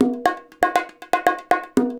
Index of /90_sSampleCDs/USB Soundscan vol.36 - Percussion Loops [AKAI] 1CD/Partition B/13-120BONGOS
120 BONGOS2.wav